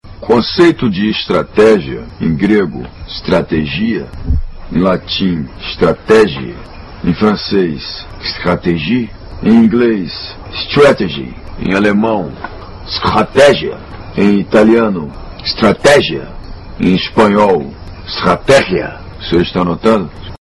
conceito estrategia meme sound effects